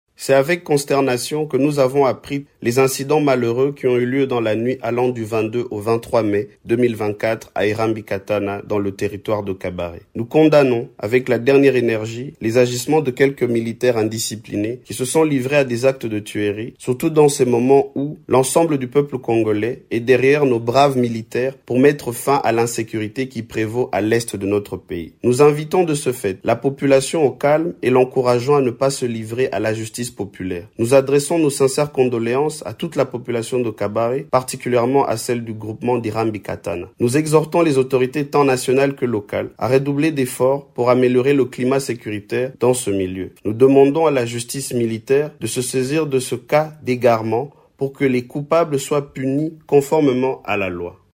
L'élu de cette circonscription appelle la justice militaire à servir contre les coupables en uniforme: